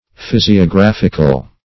Search Result for " physiographical" : The Collaborative International Dictionary of English v.0.48: Physiographic \Phys`i*o*graph"ic\, Physiographical \Phys`i*o*graph"ic*al\, a. [Cf. F. physiographique.]